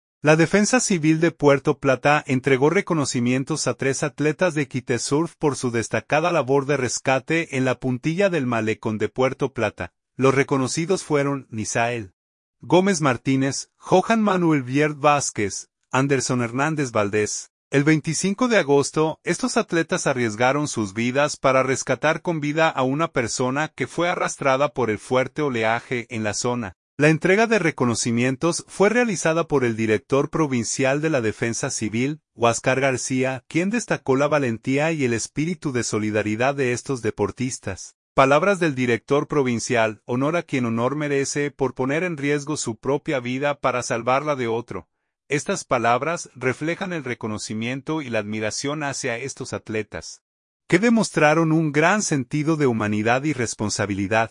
Palabras del Director Provincial: